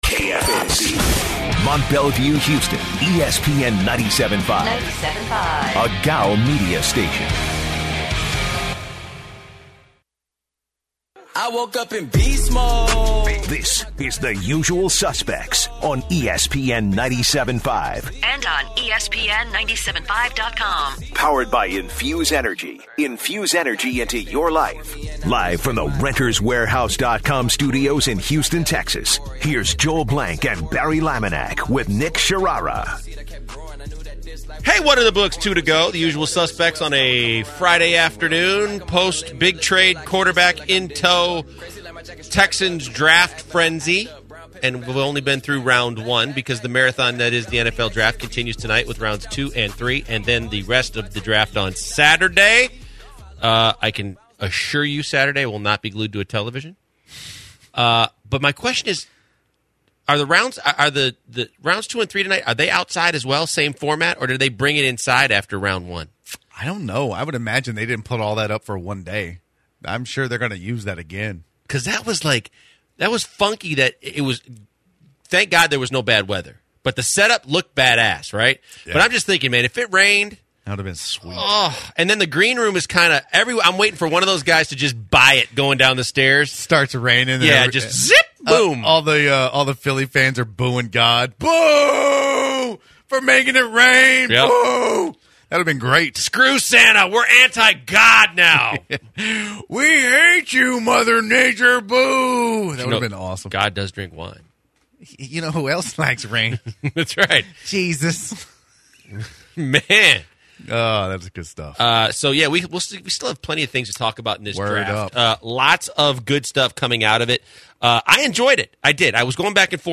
In hour two, callers weigh in to discuss last night's first round of the NFL Draft and discuss the Texans selection of Deshaun Watson